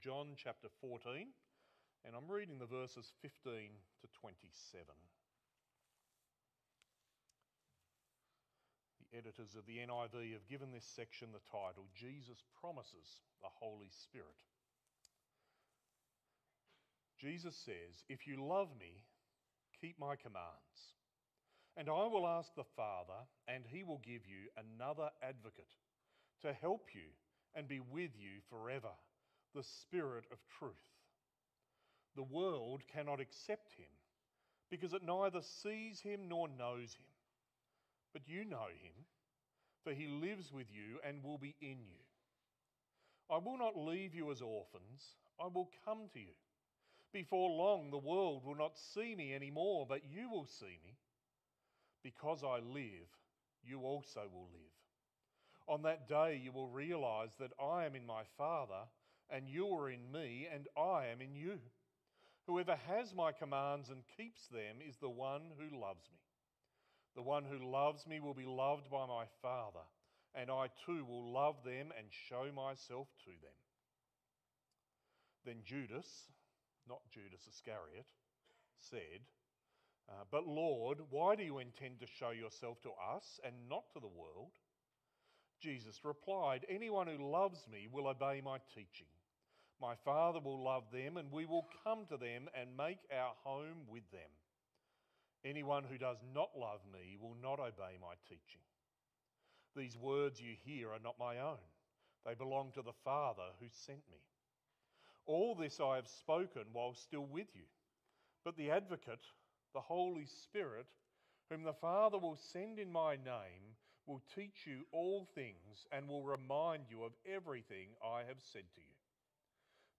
Sermons | Riverbank Christian Church